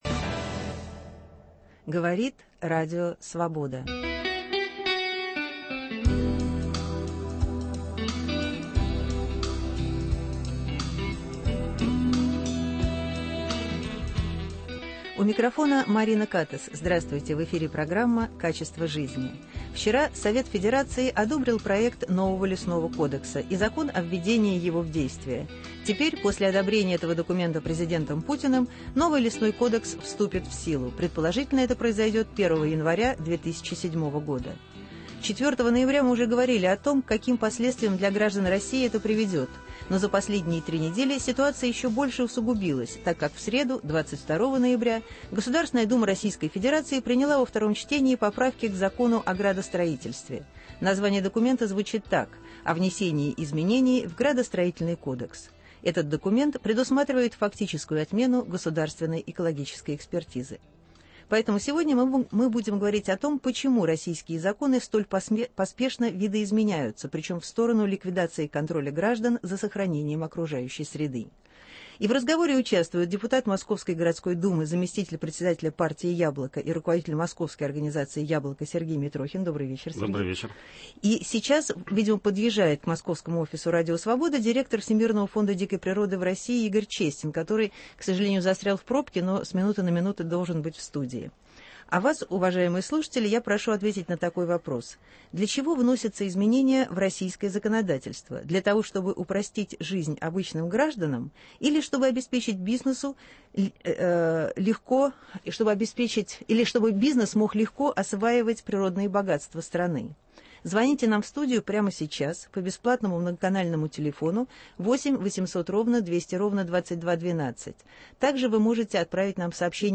Почему российские законы столь поспешно видоизменяются, причем – в сторону ликвидации контроля граждан за сохранением окружающей среды? В разговоре участвуют - депутат Московской городской Думы, заместитель председателя партии «Яблоко» и руководитель Московской организации партии «Яблоко» Сергей Митрохин